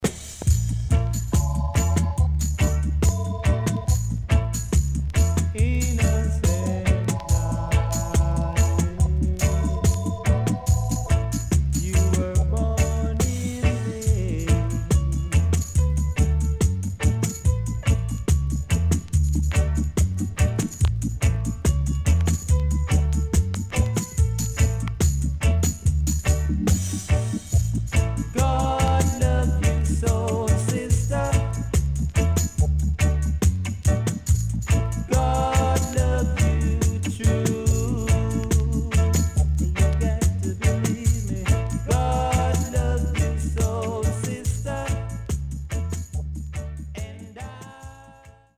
HOME > Back Order [VINTAGE DISCO45]  >  STEPPER
渋Vocal.W-Side Dubwise接続
SIDE A:うすいこまかい傷ありますがノイズあまり目立ちません。